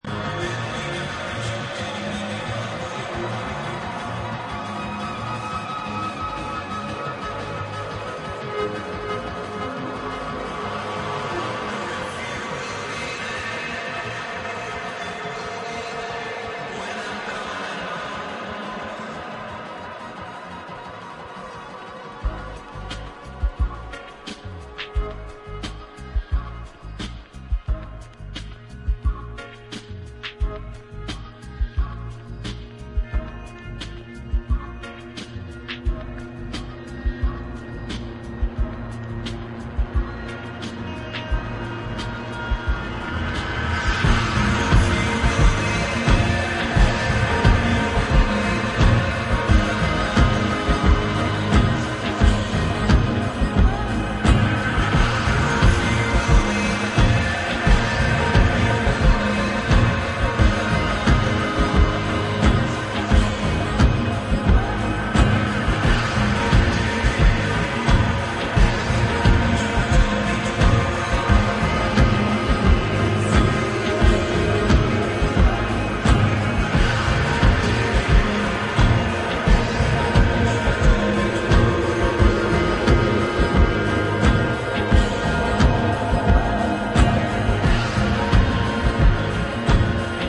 Balearic vocal gem